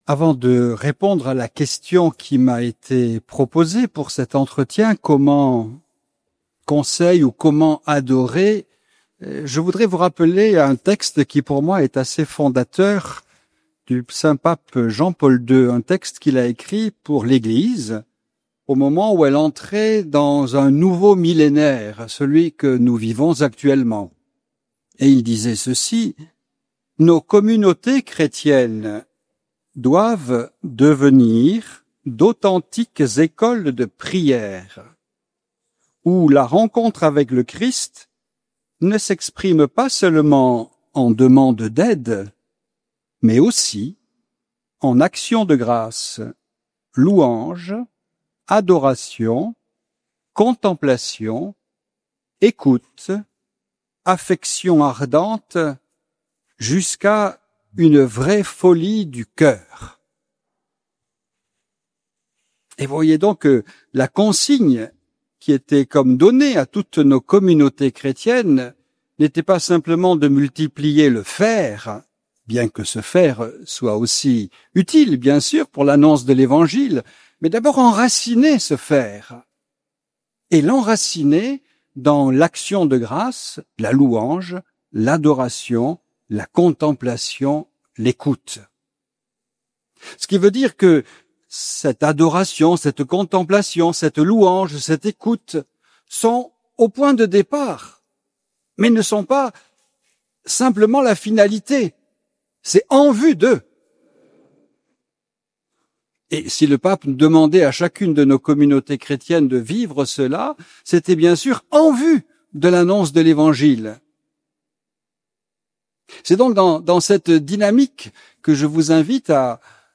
ND Laus, Congrès Adoratio